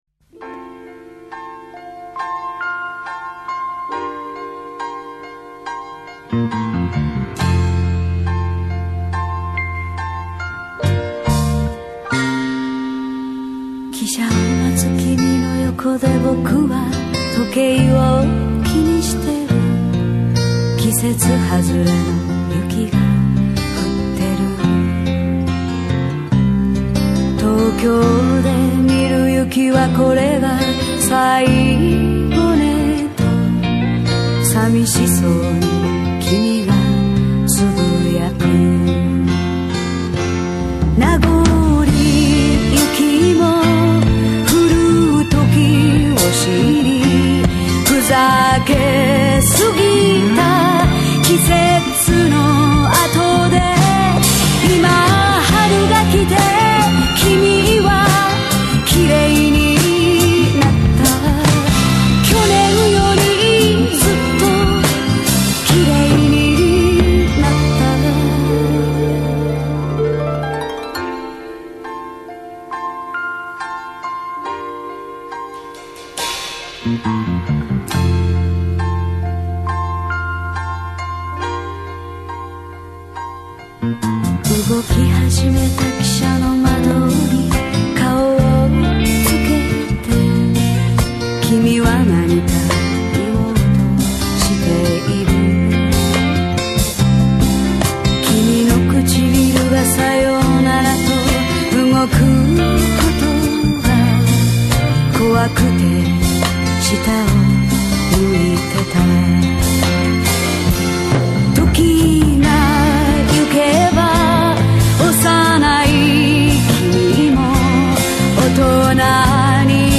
駅にまつわる歌謡曲